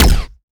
weapon_laser_006.wav